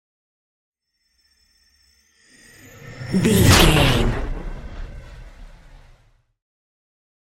Trailer dramatic whoosh to hit 452
Sound Effects
Atonal
intense
tension
woosh to hit